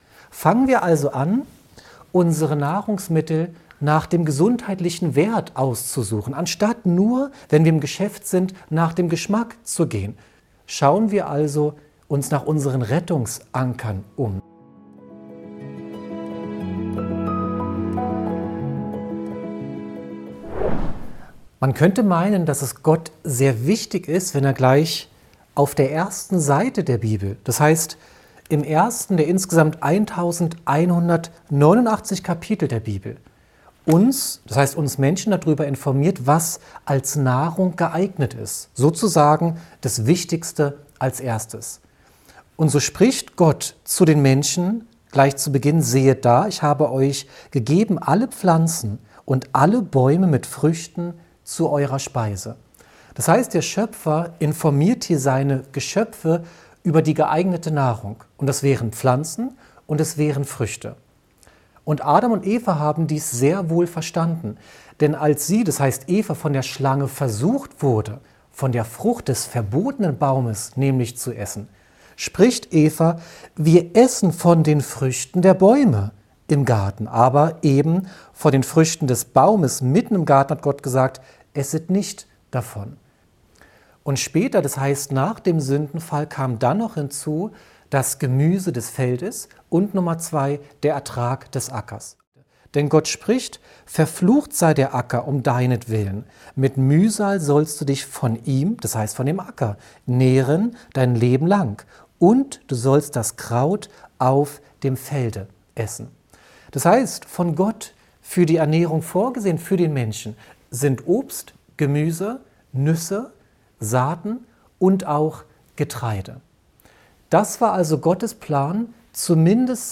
Der Schwerpunkt eines inspirierenden Vortrags über Gesundheit liegt auf der Bedeutung der richtigen Nahrungsmittelwahl. Die Ernährung, die vom biblischen Schöpfer vorgegeben ist, soll als Medizin wirken und Gesundheit fördern.